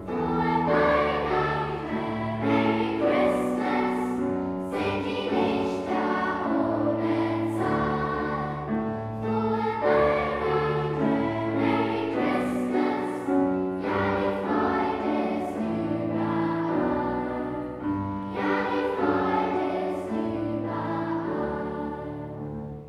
Kostproben vom Weihnachtskonzert 2024:
Singen wir im Schein der Kerzen -Unterstufenchor
Weihnachtskonzert2024_Singen_wir_im_Schein_der_Kerzen_Unterstufenchor.wav